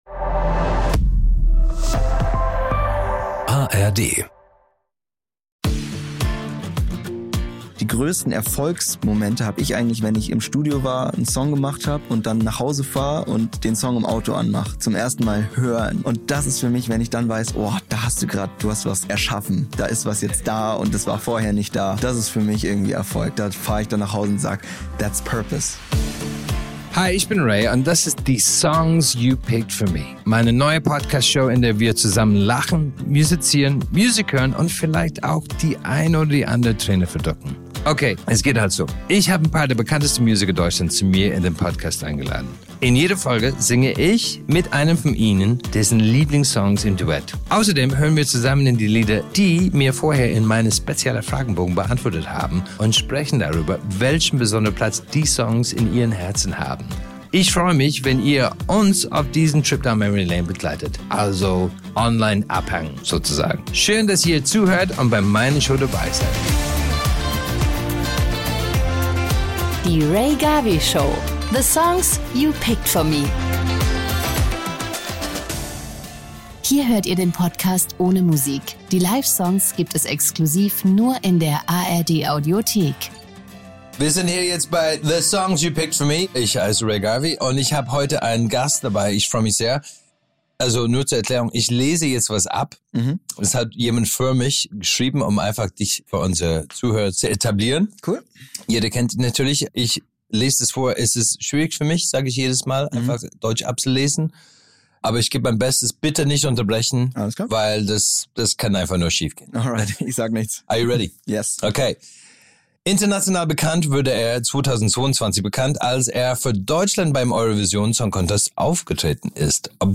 In dieser Folge trifft Rea Garvey auf den sympathischen Multiinstrumentalisten Malik Harris.